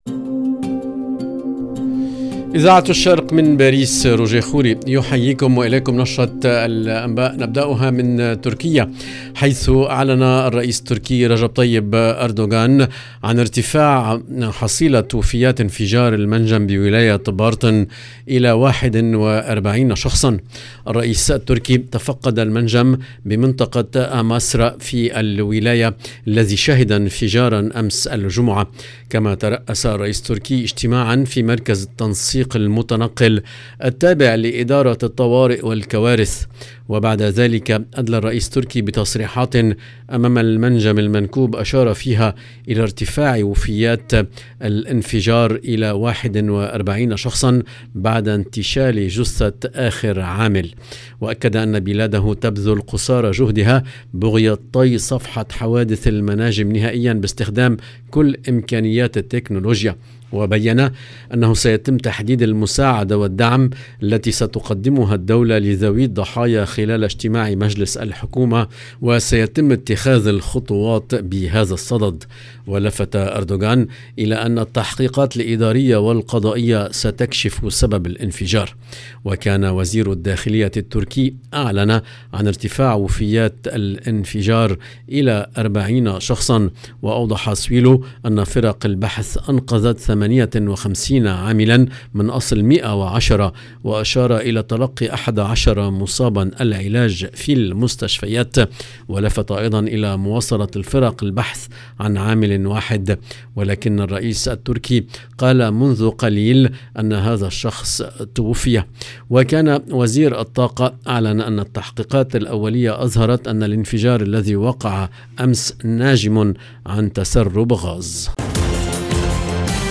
LE JOURNAL DU SOIR EN LANGUE ARABE DU 15/10/22